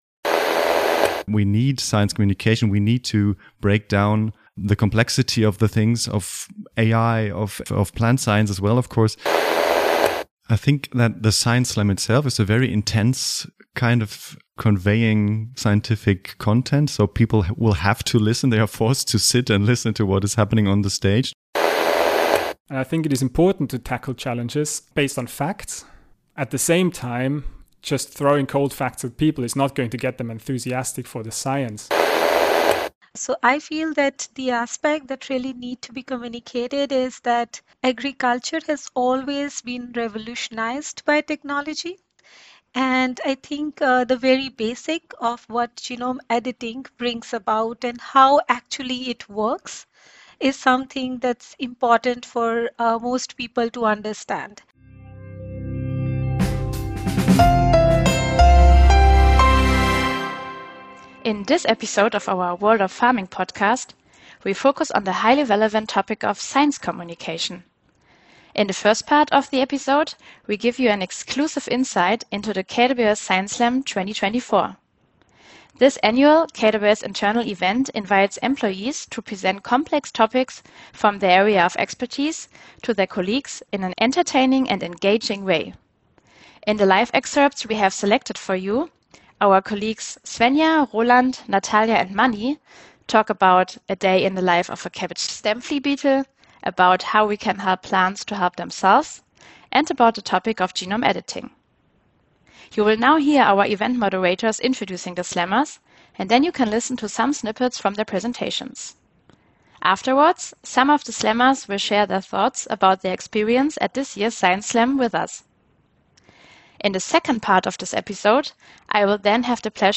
Beschreibung vor 1 Jahr In the eleventh episode we focus on the highly relevant topic of "science communication". In the first part, we offer exclusive impressions of the "KWS Science Slam 2024". This annual internal KWS event invites employees to present complex topics in their field in an entertaining and accessible way to their colleagues.